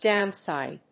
音節dówn・sìde 発音記号・読み方
/ˈdaʊˌnsaɪd(米国英語)/